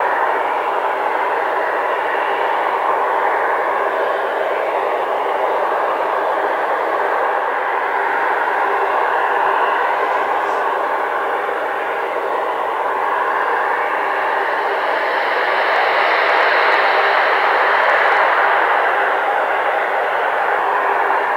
Add external IAE sounds
v2500-idleDist.wav